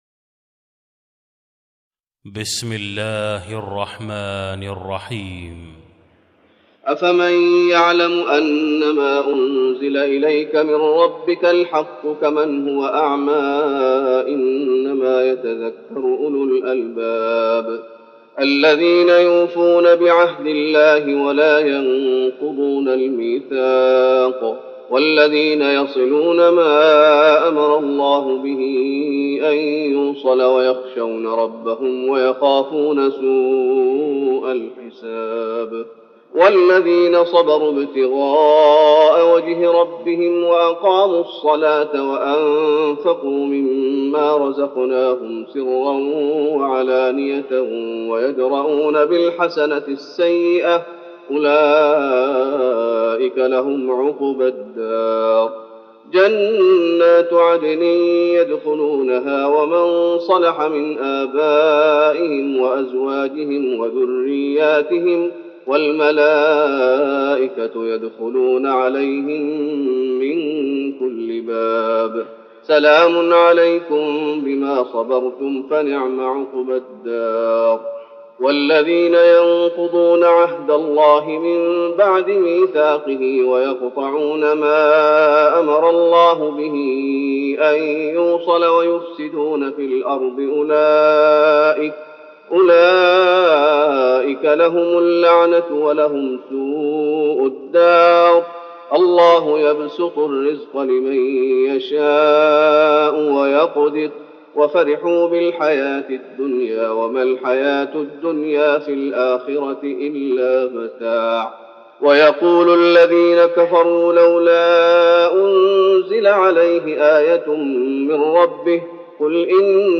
تراويح رمضان 1413هـ من سورة الرعد (19-43) Taraweeh Ramadan 1413H from Surah Ar-Ra'd > تراويح الشيخ محمد أيوب بالنبوي 1413 🕌 > التراويح - تلاوات الحرمين